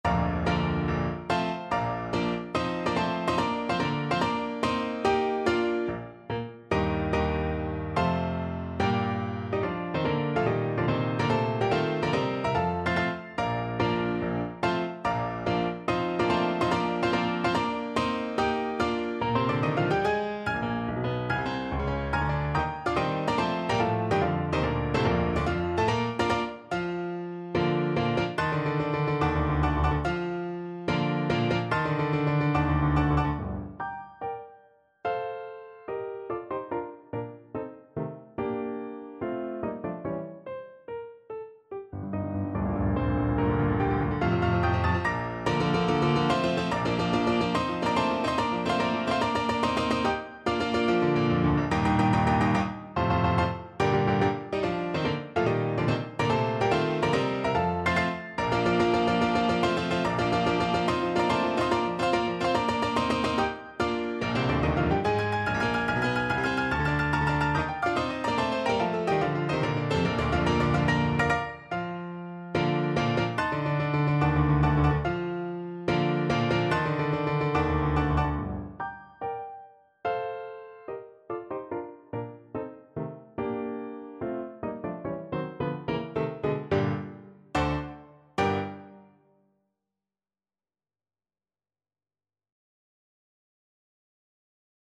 No parts available for this pieces as it is for solo piano.
Allegro non troppo (=72) (View more music marked Allegro)
4/4 (View more 4/4 Music)
Piano  (View more Advanced Piano Music)
Classical (View more Classical Piano Music)